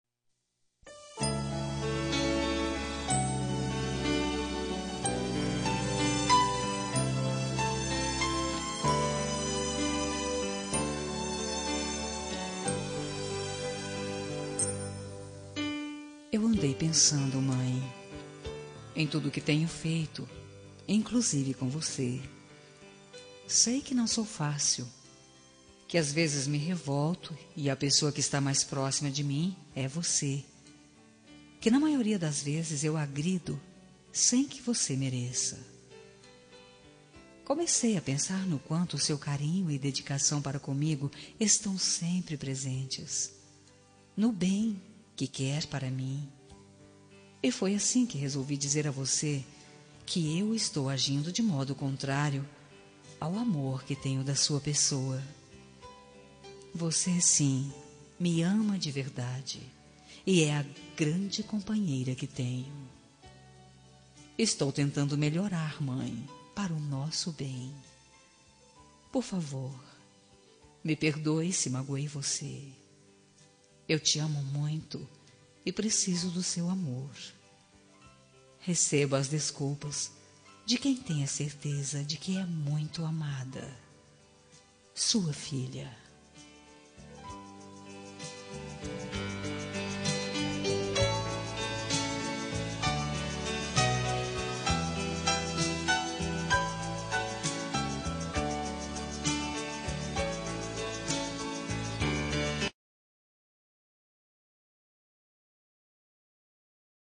Reconciliação Familiar – Voz Masculina – Cód: 088734 – Mãe